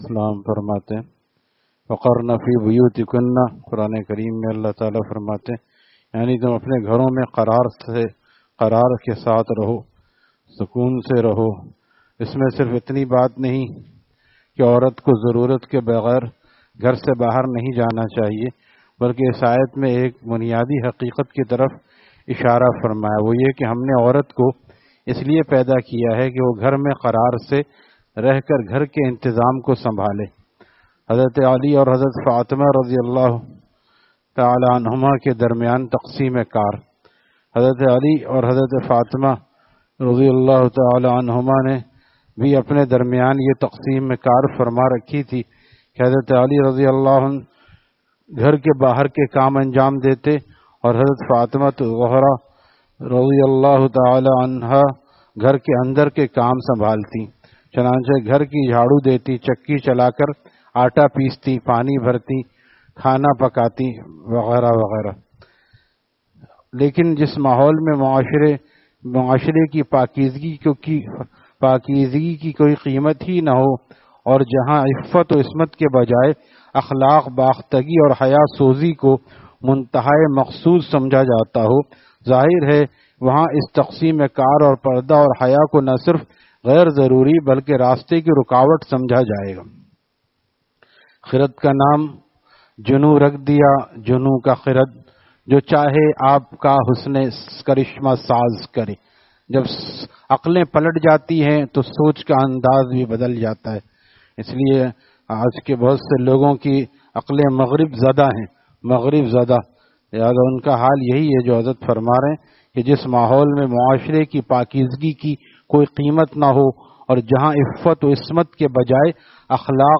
Taleem After Fajor at Jamia Masjid Gulzar e Muhammadi, Khanqah Gulzar e Akhter, Sec 4D, Surjani Town